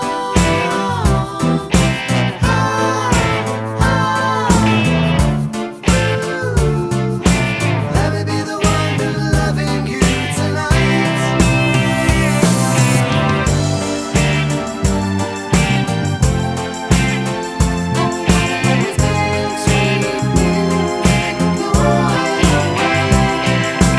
Minus Main Guitar For Guitarists 2:56 Buy £1.50